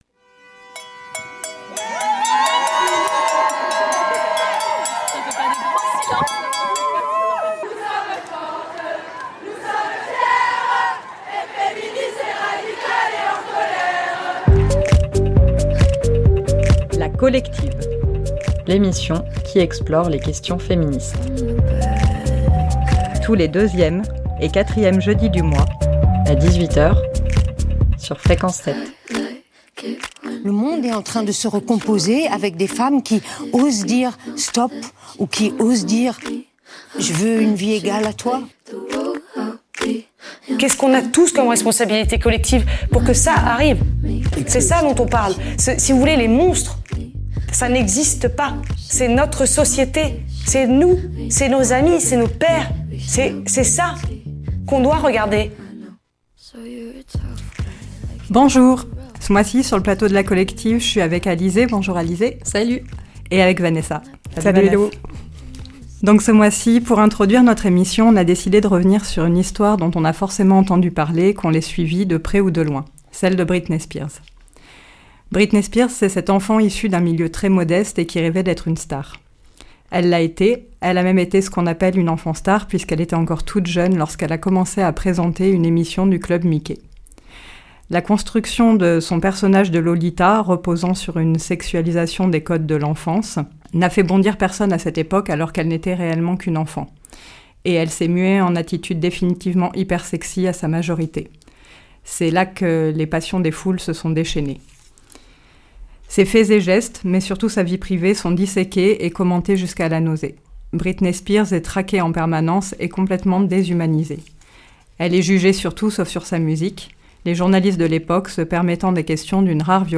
Pour tenter de s'y retrouver, nous vous proposons une émission mensuelle qui, sans prétention, regarde, interroge et explore le monde au prisme du genre. Des témoignages, des entretiens, des reportages, des lectures et de la musique pour prendre conscience que les dominations sont multiples et que le féminisme peut transformer notre regard sur le monde et nous-même.
Cette fois, on parle des discrimination dans l'accès aux soins et on vous propose de revolutionner le système de santé; tout ça en 40 minutes avec en prime un peu de punk, quelques MST et un zeste de graines de chia.